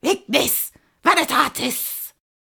mandrake fvttdata/Data/modules/psfx/library/incantations/older-female/fire-spells/ignis-veritatis
ignis-veritatis-commanding.ogg